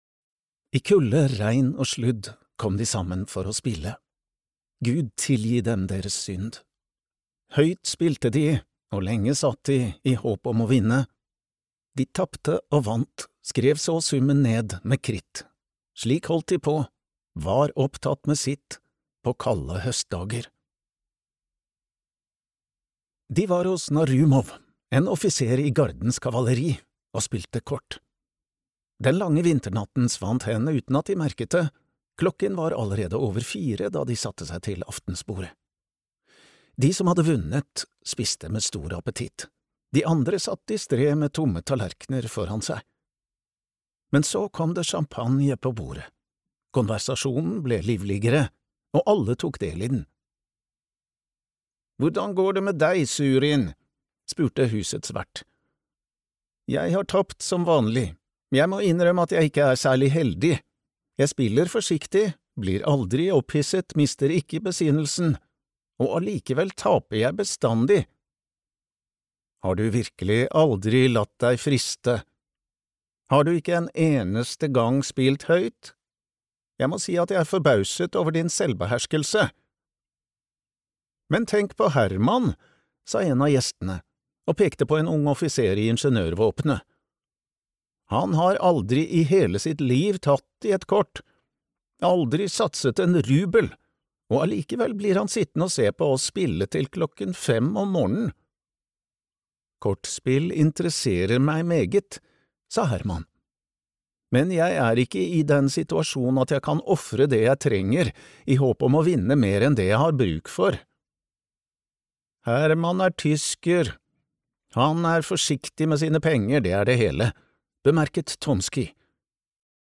Spar dame (lydbok) av Aleksandr Puškin